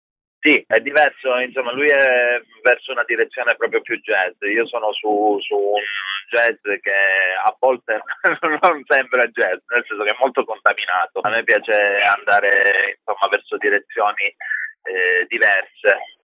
La telefonata